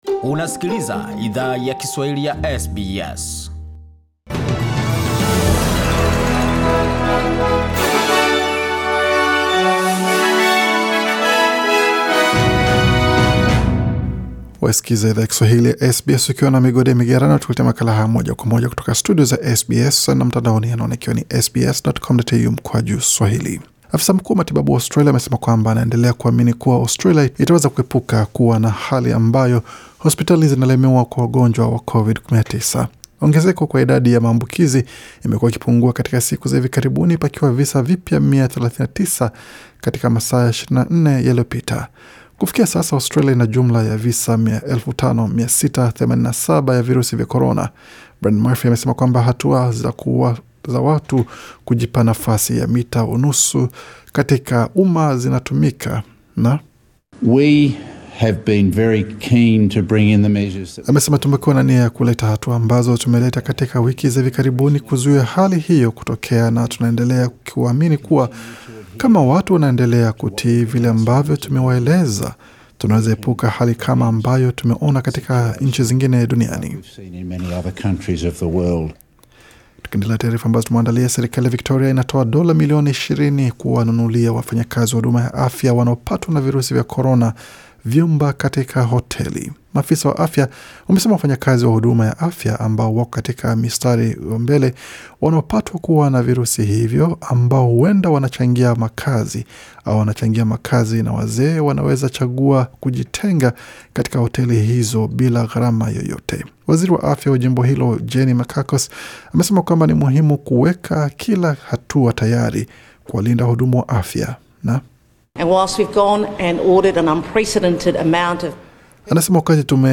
Taarifa za habari: Meli ya Ruby Princess kufunguliwa uchunguzi wa jinai